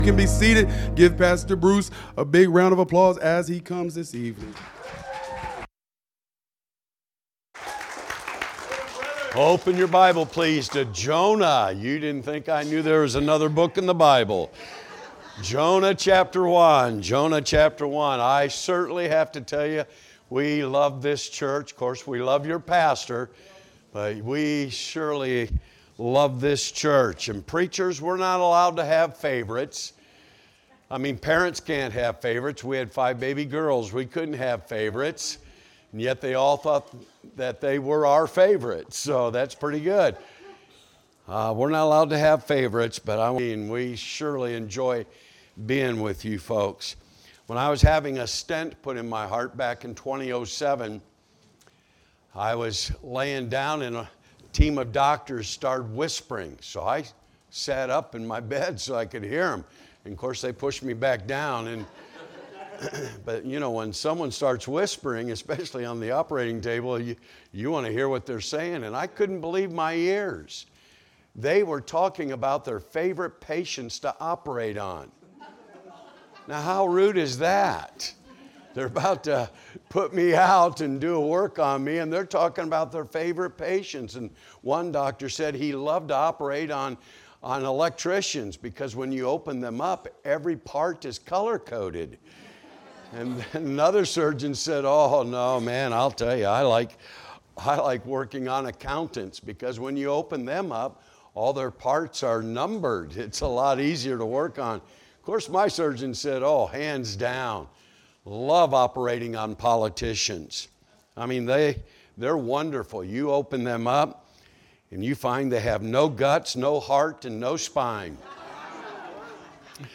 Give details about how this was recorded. Wednesday Evening Revival Service